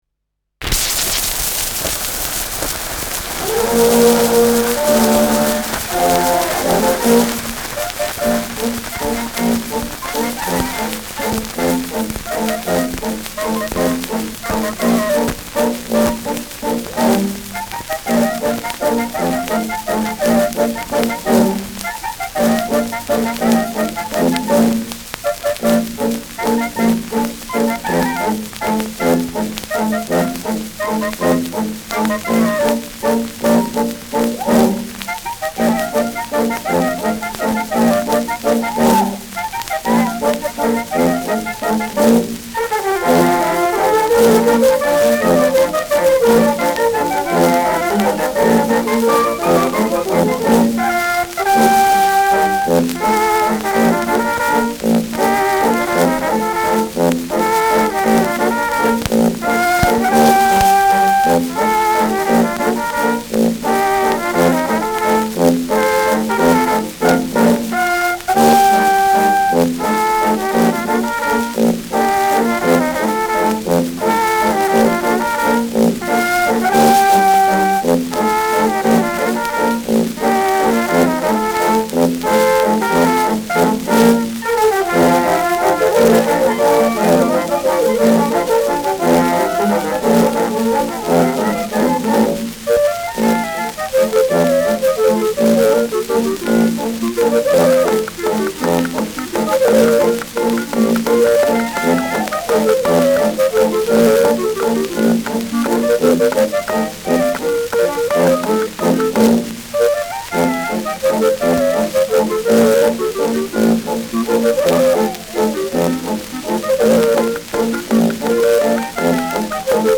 Schellackplatte
starkes bis präsentes Rauschen : präsentes Knistern : Knacken zu Beginn : leichtes Leiern : abgespielt : häufiges Knacken
Mit Juchzern und Klopfgeräuschen.
[München] (Aufnahmeort)